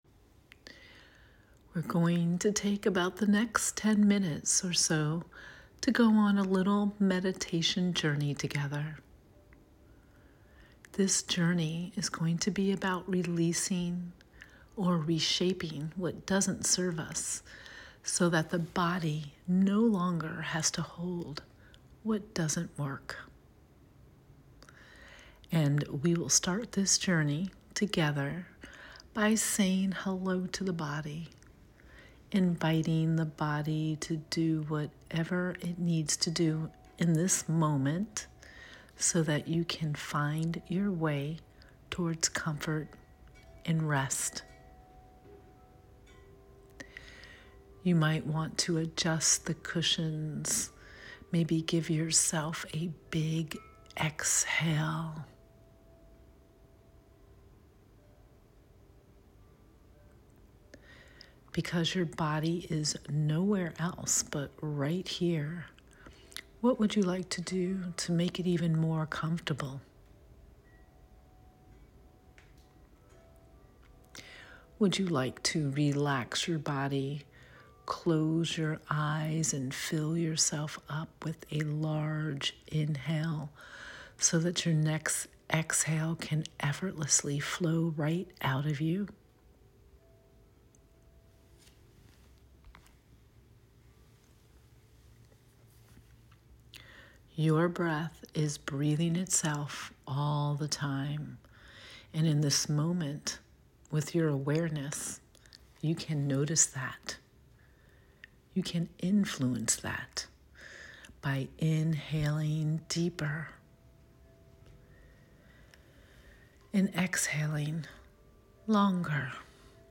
BONUS: Guided Release Meditation